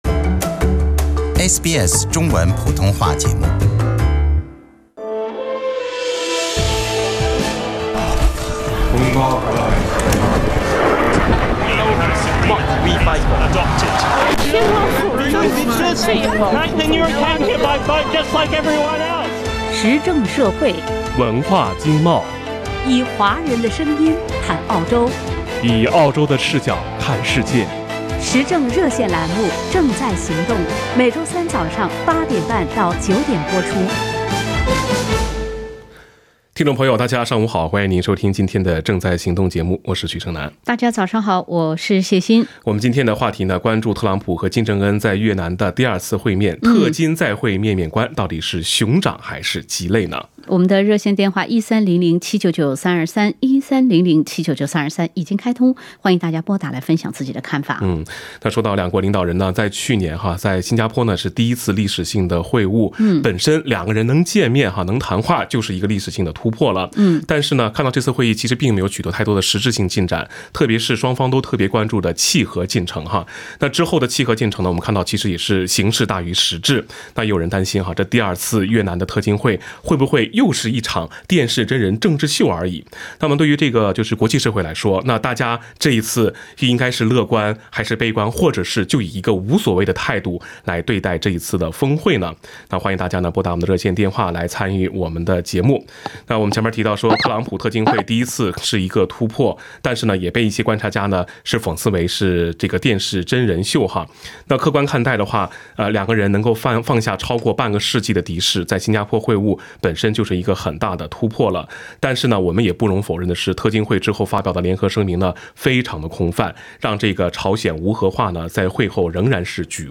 时政热线节目《正在行动》逢周三上午8点30分至9点播出。